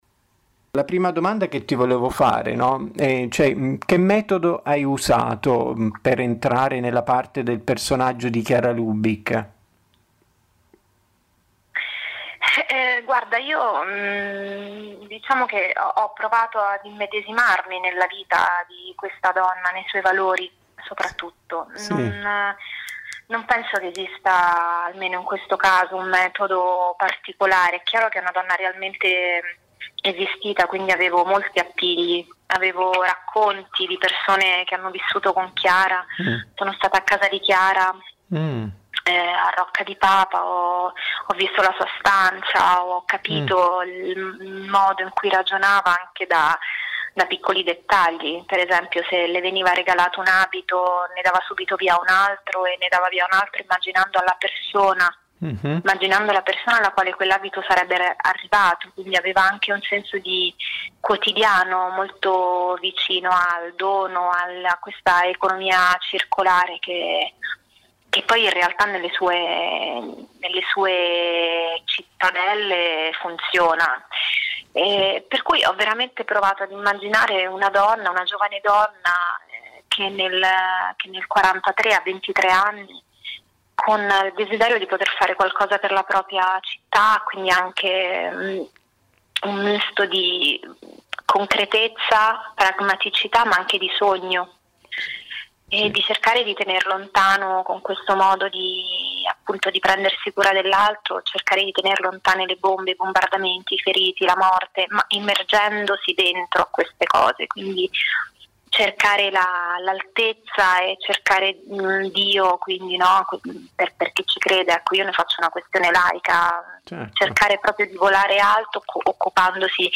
Nostra intervista alla protagonista Cristiana Capotondi che interpreta la fondatrice del Movimento dei Focolari